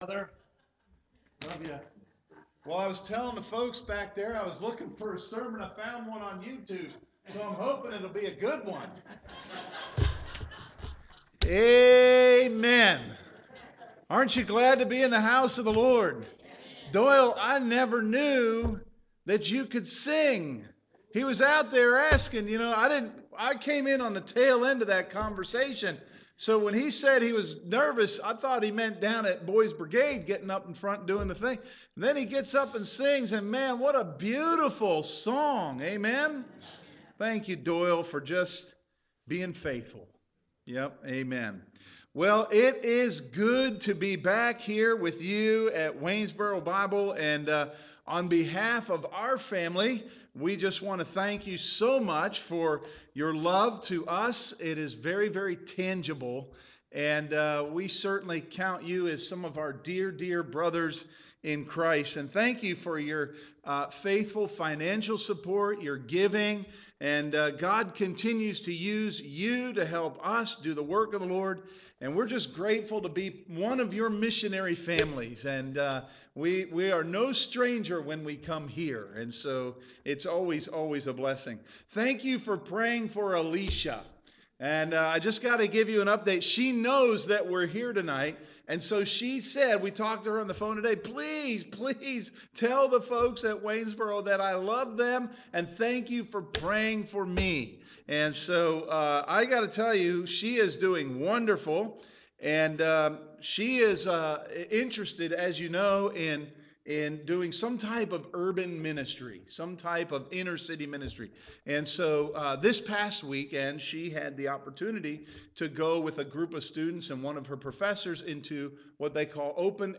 2022 Missions Conference PM
Sermon